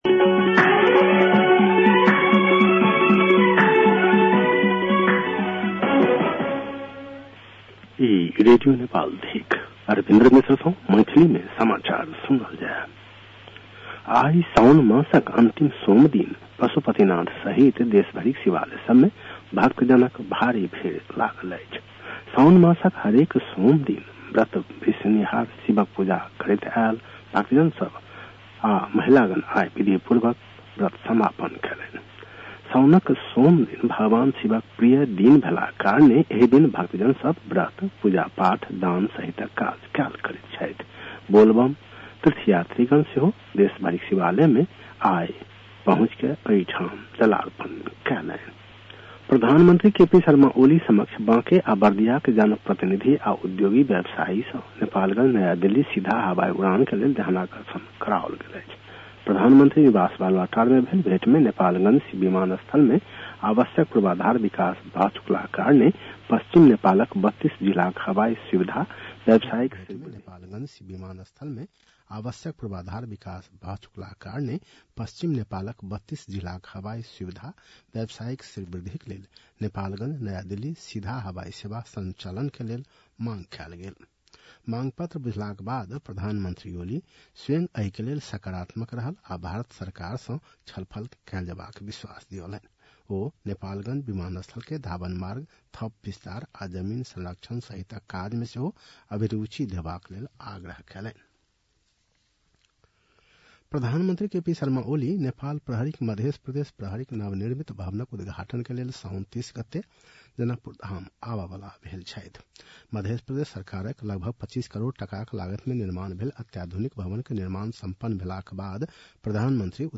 मैथिली भाषामा समाचार : २६ साउन , २०८२
6-pm-maithali-news-.mp3